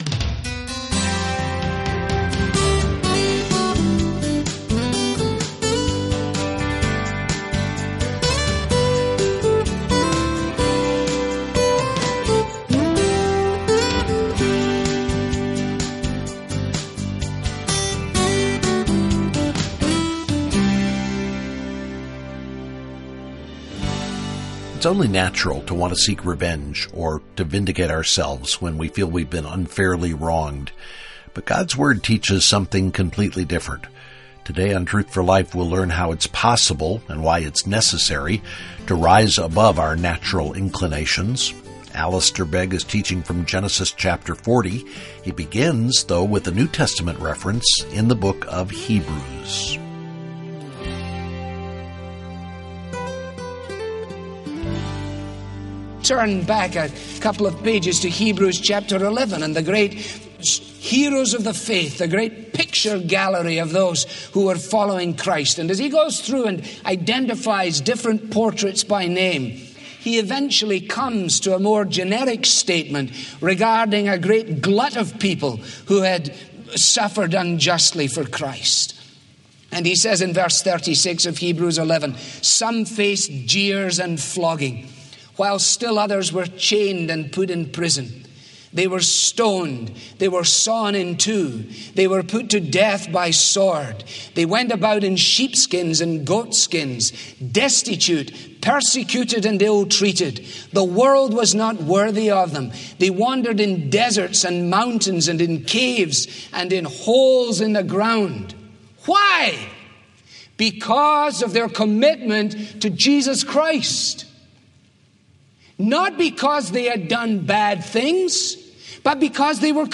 • This program is part of the sermon ‘The Hand of God, Volume 1’ • Learn more about our current resource, request your copy with a donation of any amount.